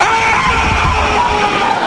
Funny Scream Aaaaaaaaaaaaaaah